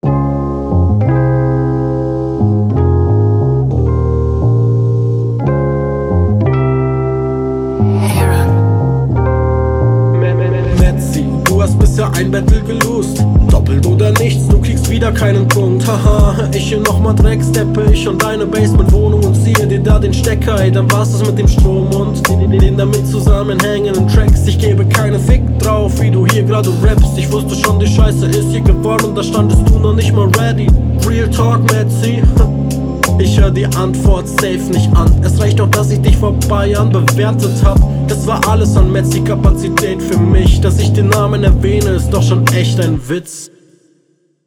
Beat zu laut?